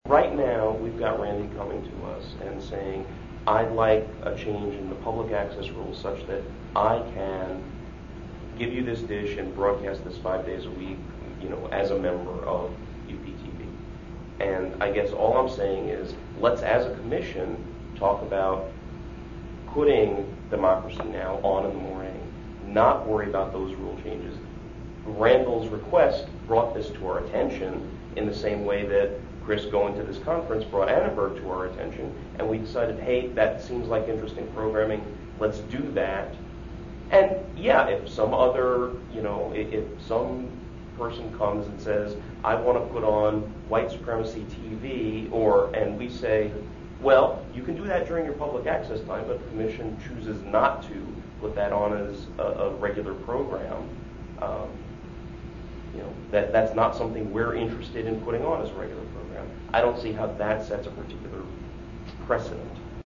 Audio clip from the meeting: